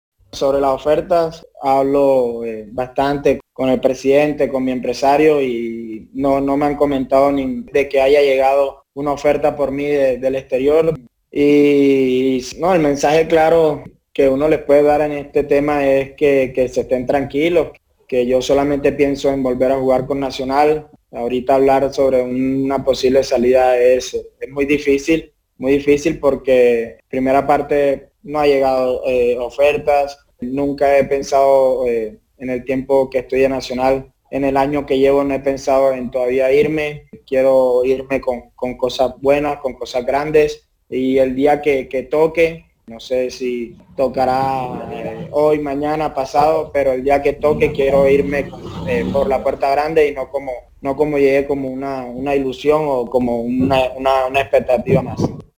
(Jarlan Barrera, jugador de Atlético Nacional)
"Estén tranquilos, yo solamente pienso en volver a jugar con Nacional. Hablo permanentemente con el presidente y con mi empresario, y no me han comentado que haya llegado alguna oferta por mí del exterior", sostuvo Barrera en una rueda de prensa virtual.